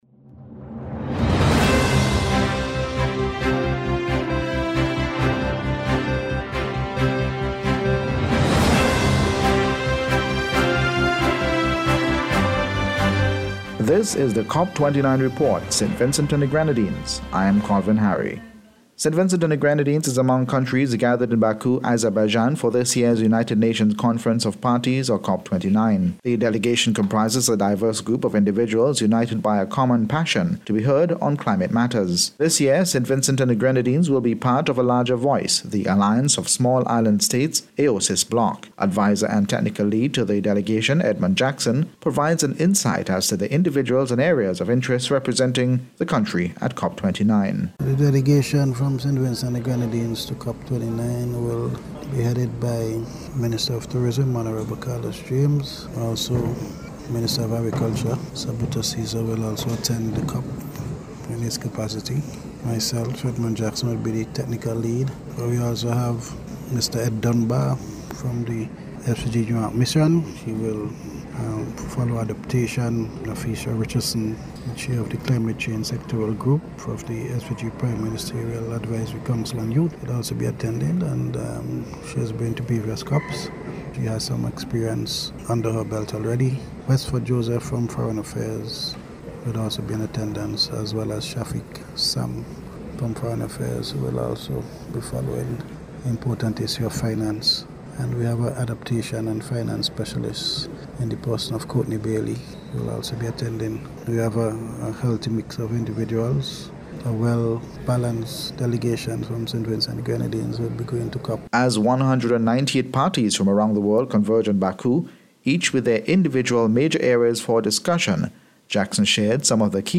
COP29-Report-St-Vincent-and-the-Grenadines-1.mp3